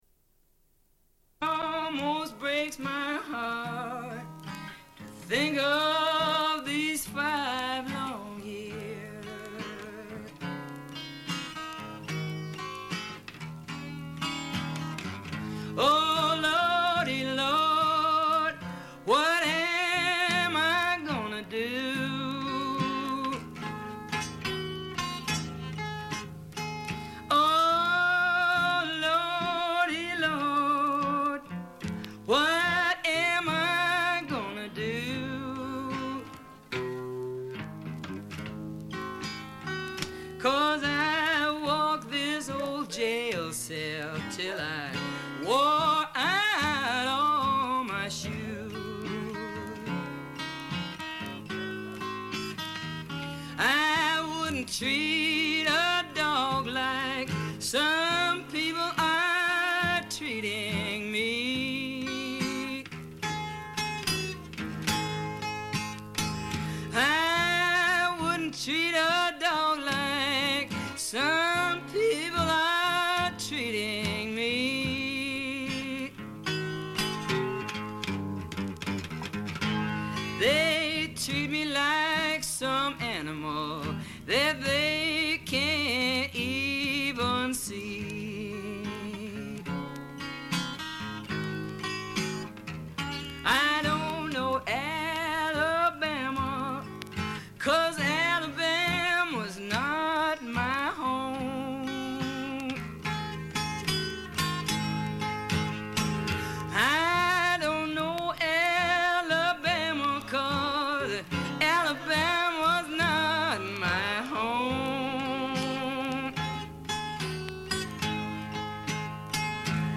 Une cassette audio, face A47:19